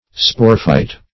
Sporophyte \Spo"ro*phyte\ (sp[=o]"r[-o]*f[imac]t), n. [Spore +